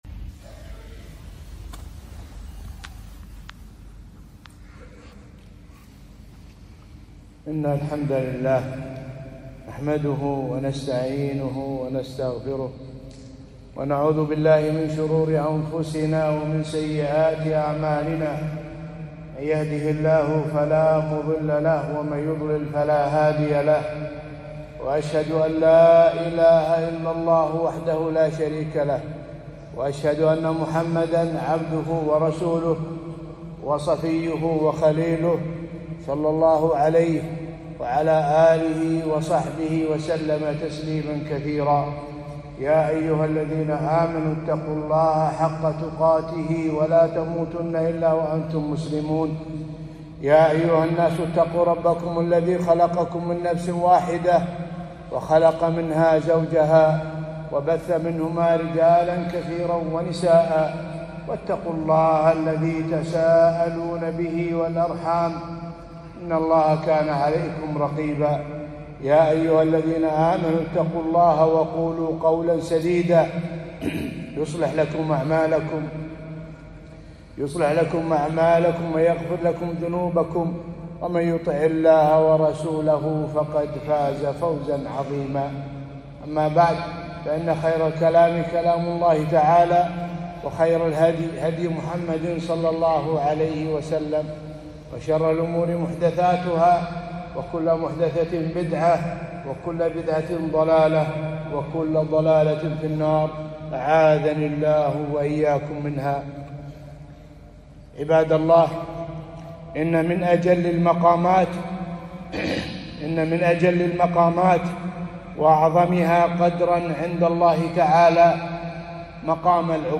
خطبة - مرتبة العبودية